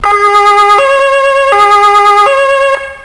Sirenensignale/Martinshorn
Horn2.mp3